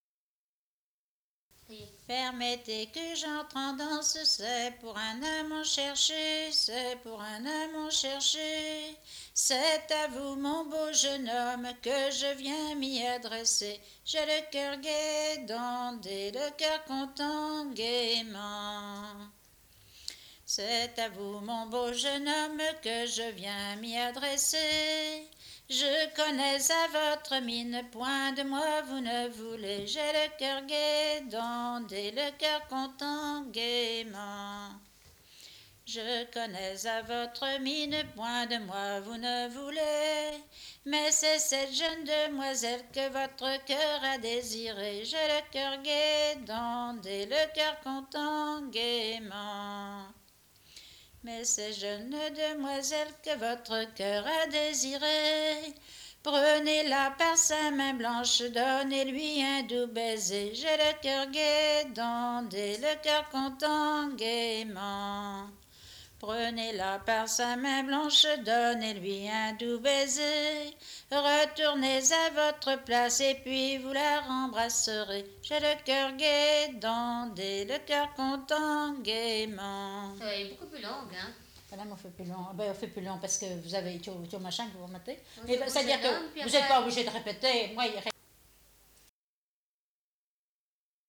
gestuel : danse
Pièce musicale inédite